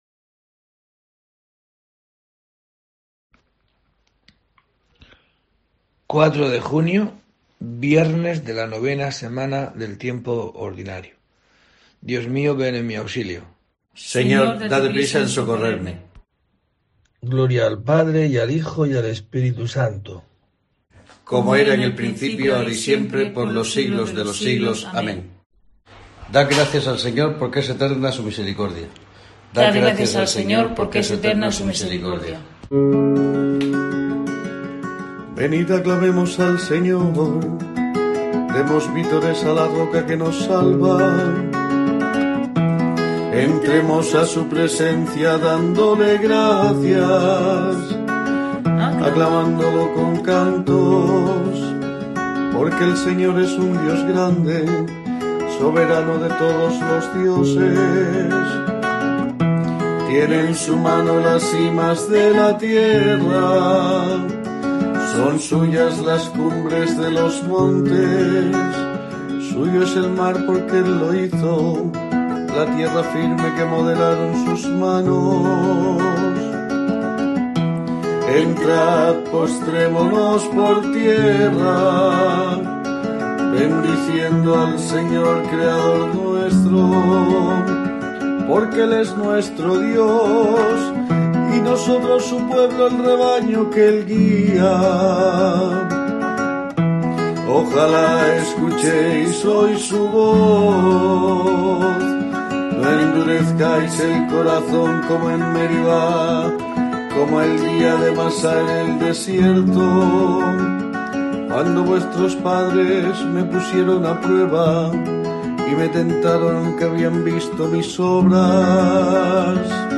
04 de junio: COPE te trae el rezo diario de los Laudes para acompañarte